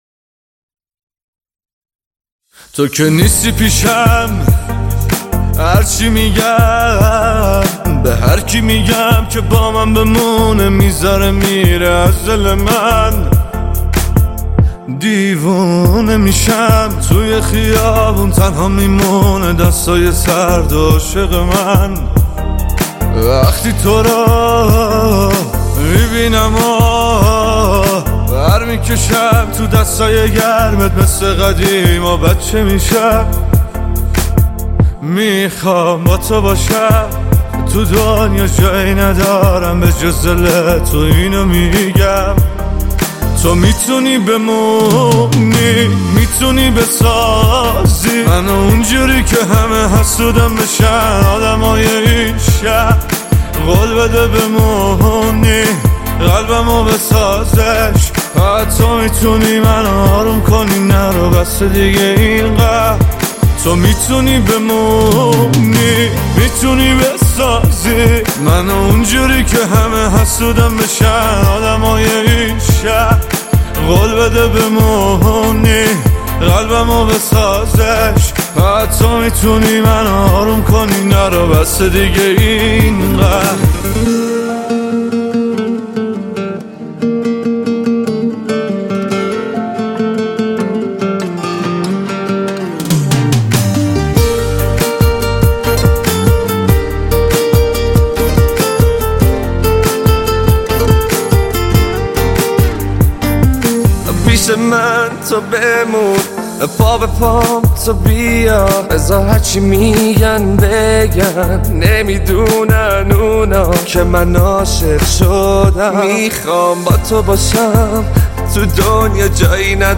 دانلود آهنگ شاد با کیفیت ۱۲۸ MP3 ۳ MB